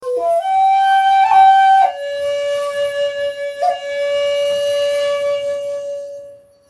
Shakuhachi 63